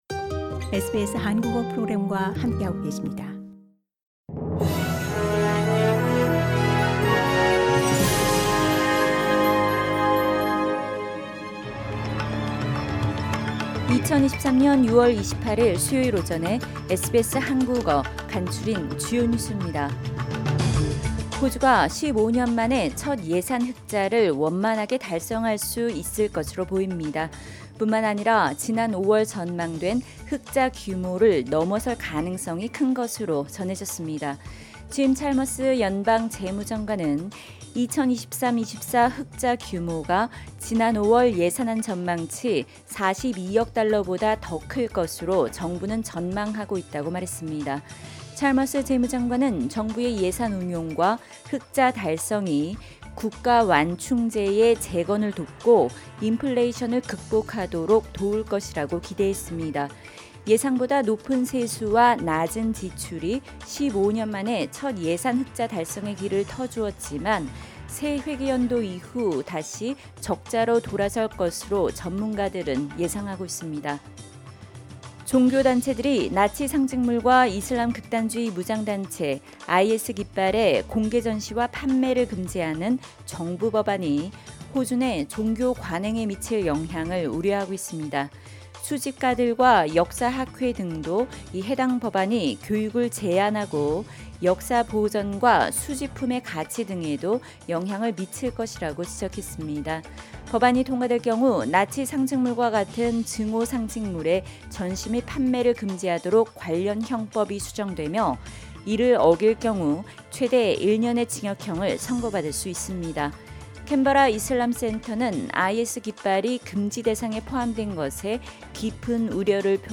SBS 한국어 아침 뉴스: 2023년 6월 28일 수요일